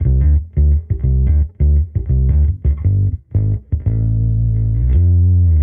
Index of /musicradar/sampled-funk-soul-samples/85bpm/Bass
SSF_PBassProc1_85D.wav